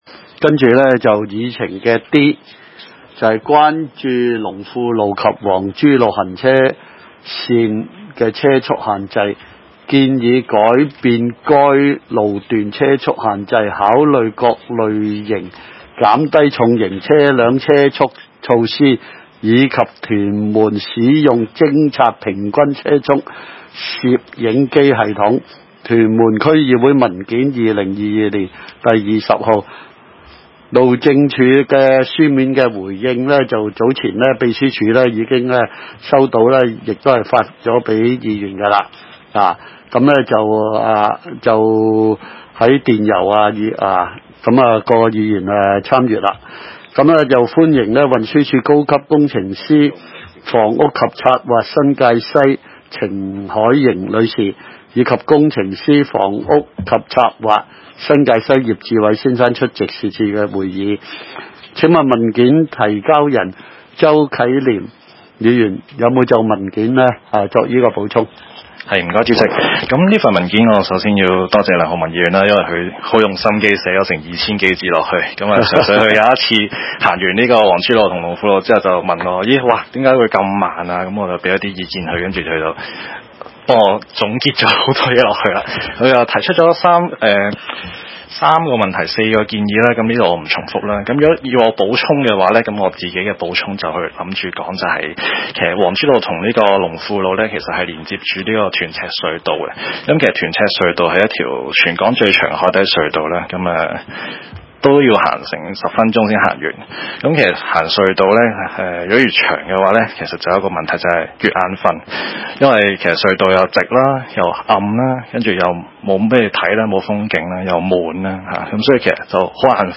屯門區議會 - 區議會大會的錄音記錄
會議的錄音記錄